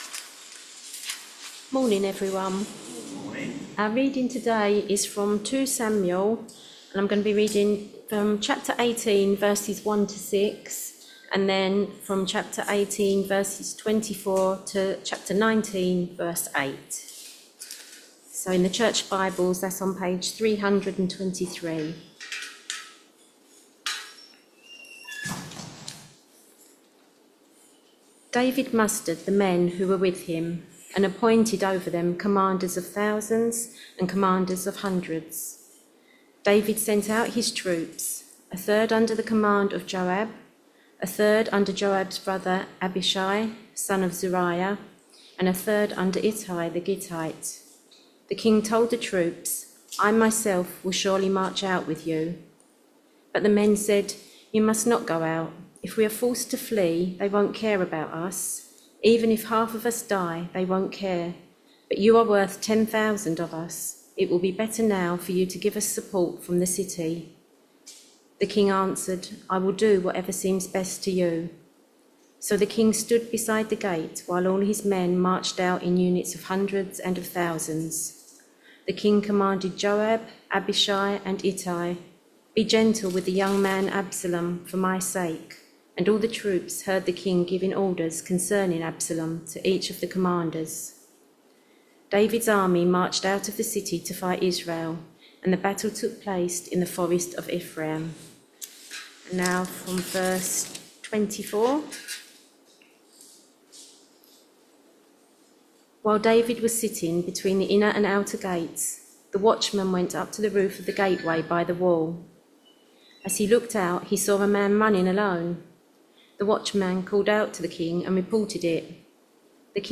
2 Samuel 18vv1-6,18v24-19v8 Service Type: Sunday Morning Service Topics